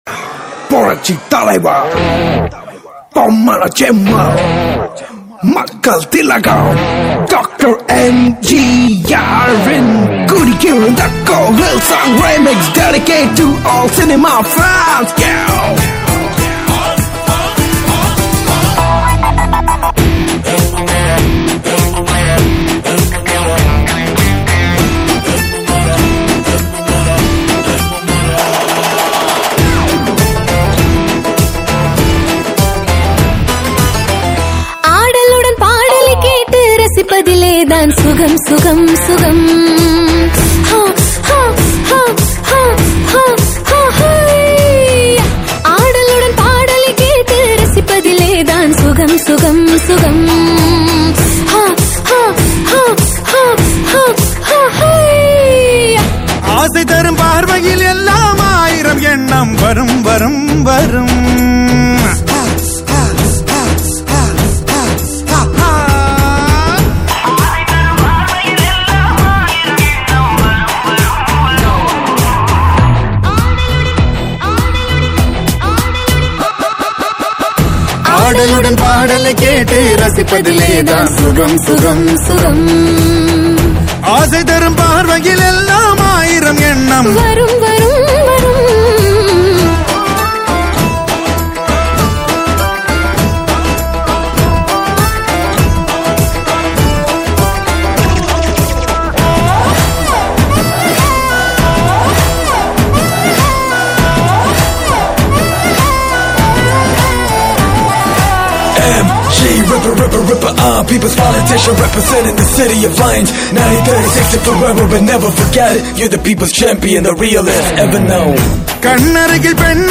TAMIL ITEM DJ REMIX SONG